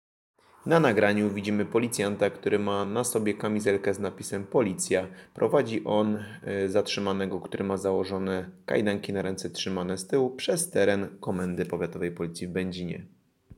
Nagranie audio Audiodeskrypcja_filmu.mp3